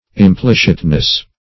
\Im*plic"it*ness\